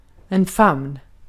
Ääntäminen
US : IPA : [ˈfæð.əm]